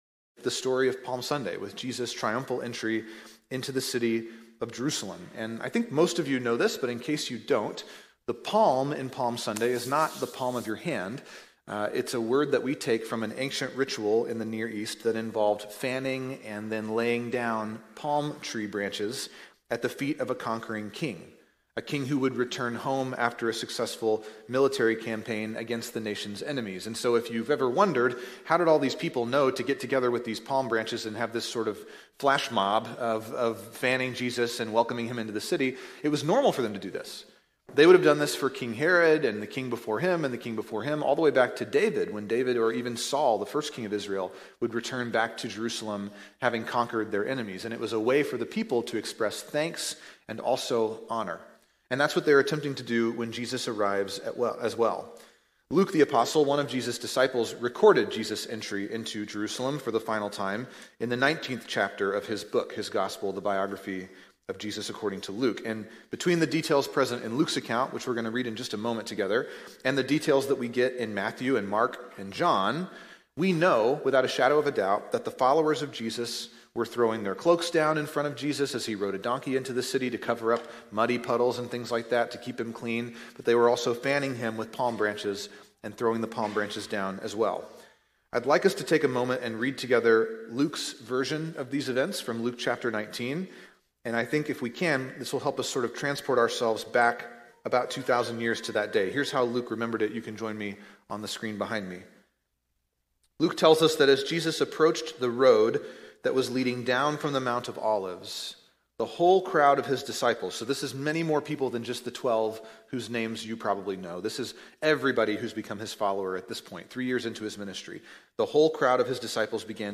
True North Church's weekly sermon feed.